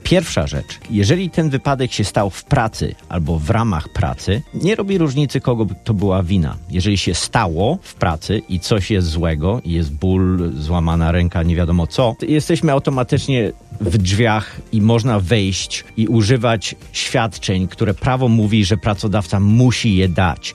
W studiu Radia Deon Chicago